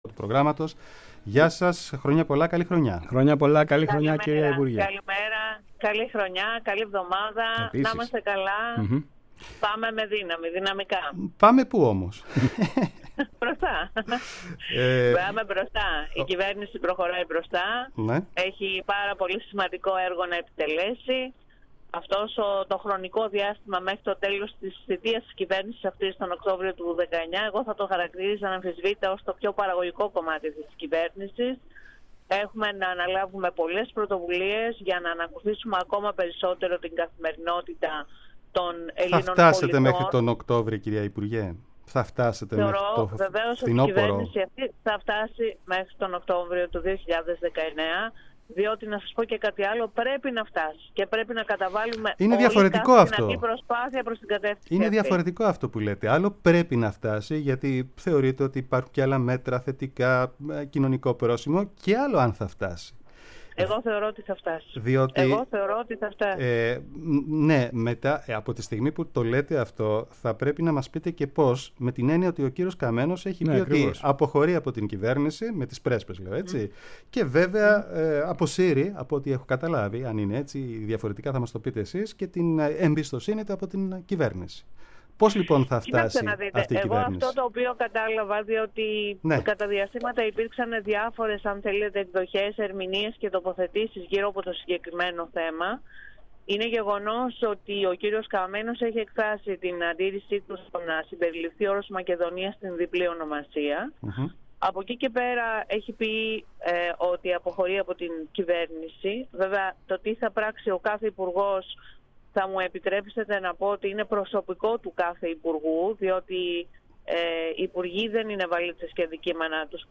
Συνέντευξη Υφυπουργού Εσωτερικών στον REAL FM